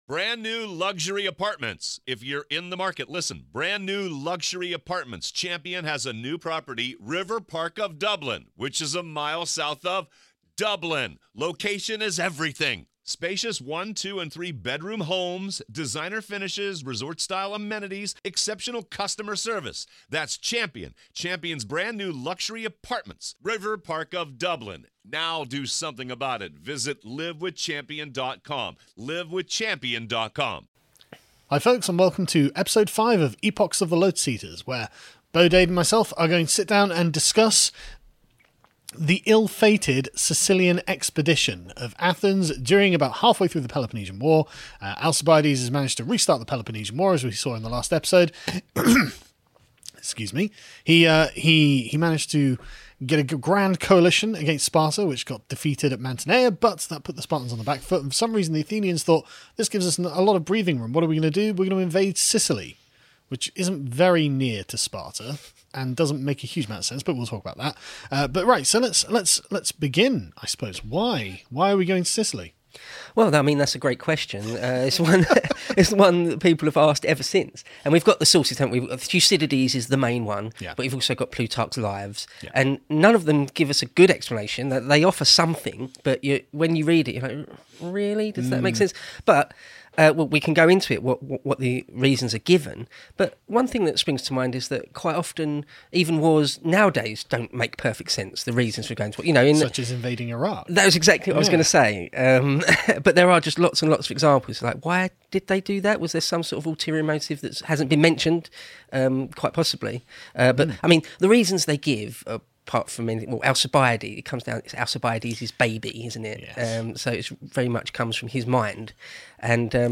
chat about the Athenian's doomed Sicilian Expedition during the Peloponnesian War and Alcibiades' role in it, as related by Thucydides and Plutarch.